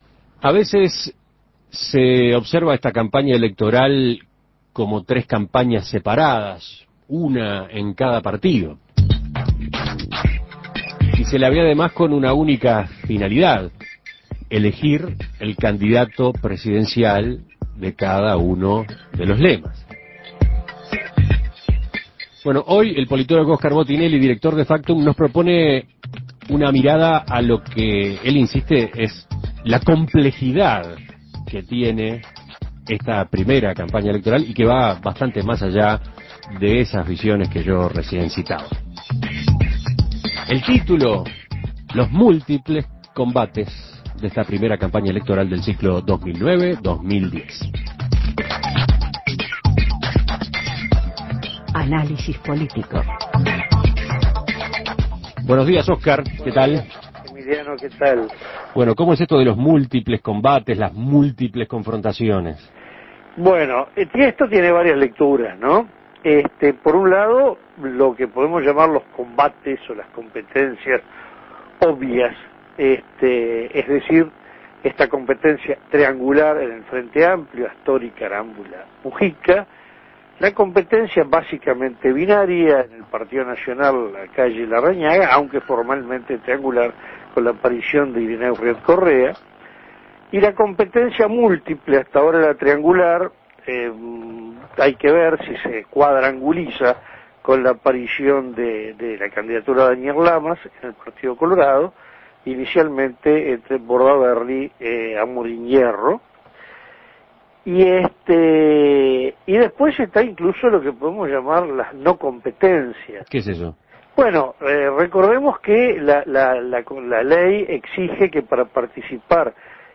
Análisis Político